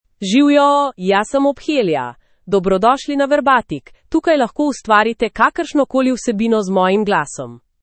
Ophelia — Female Slovenian AI voice
Ophelia is a female AI voice for Slovenian (Slovenia).
Voice sample
Female
Ophelia delivers clear pronunciation with authentic Slovenia Slovenian intonation, making your content sound professionally produced.